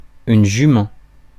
Ääntäminen
Ääntäminen France: IPA: [yn ʒy.mɑ̃] Tuntematon aksentti: IPA: /ʒy.mɑ̃/ Haettu sana löytyi näillä lähdekielillä: ranska Käännös Ääninäyte Substantiivit 1. mare US 2. horse UK US Suku: f .